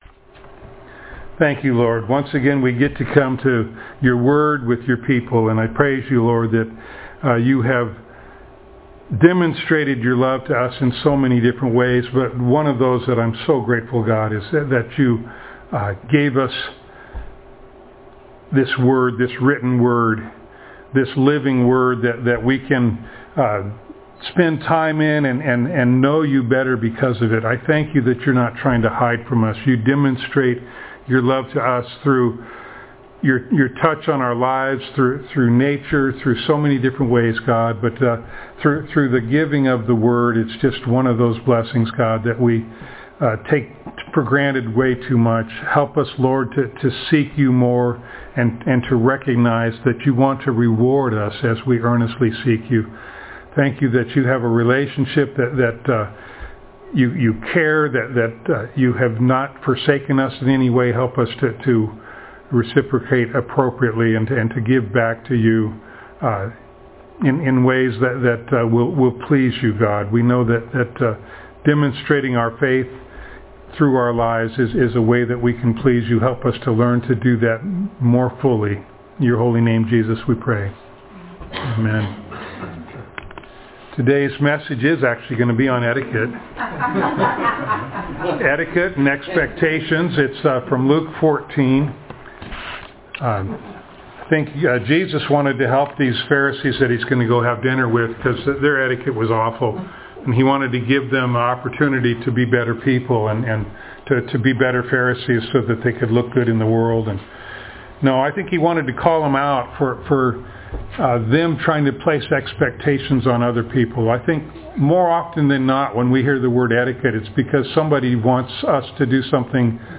Luke Passage: Luke 14:1-24 Service Type: Sunday Morning Download Files Notes « Set Free